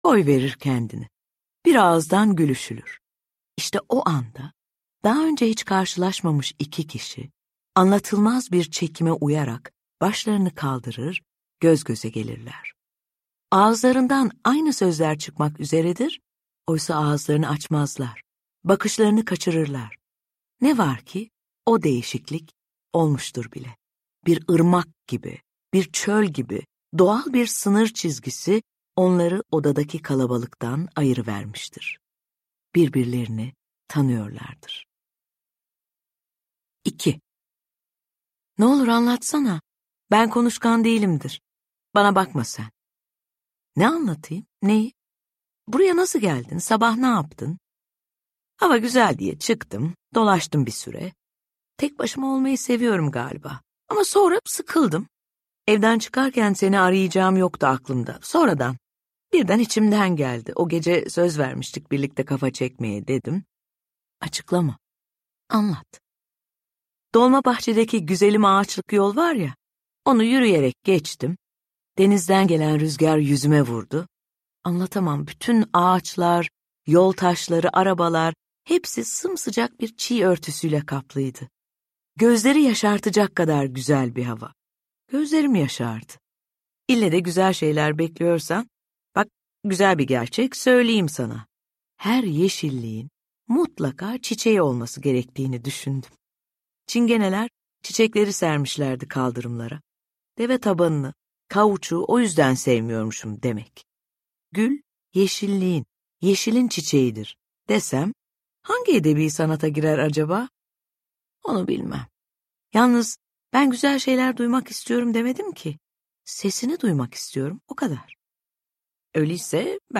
Seslendiren
TİLBE SARAN